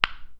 bounce.wav